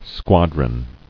[squad·ron]